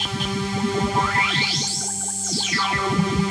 synth01.wav